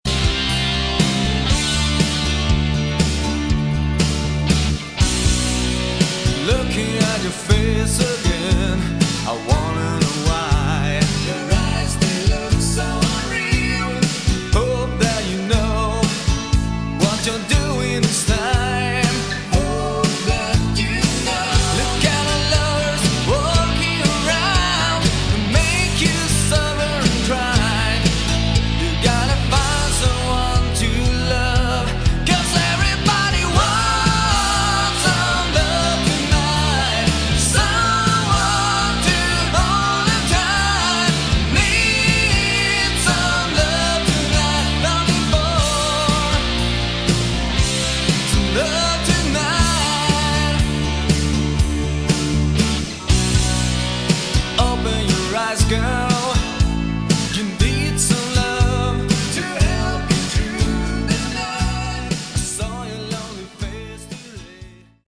Category: Hard Rock
Vocals, Guitar, Bass, Keyboards, Drums